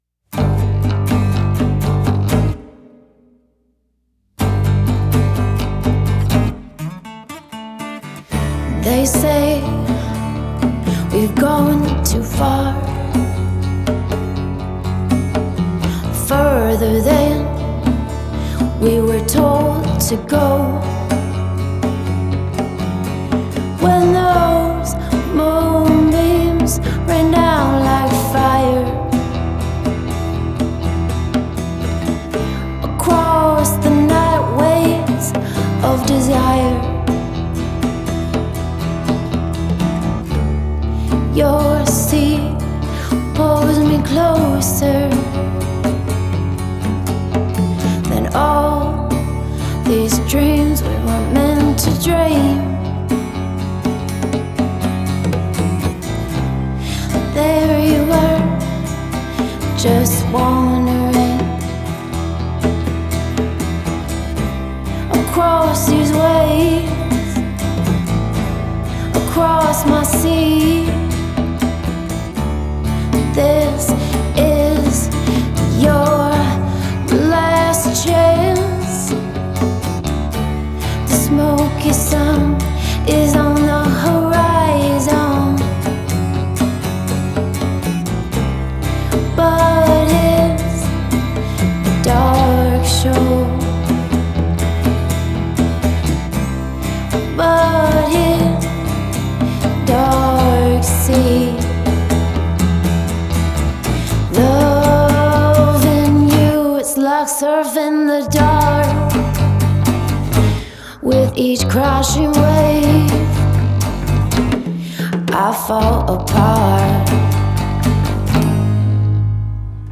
Acoustic recorded single